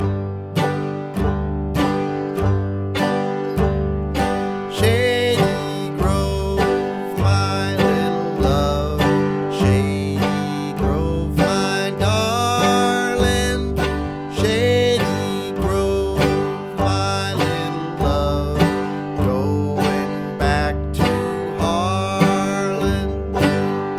super-slow (key of G)
Super-slow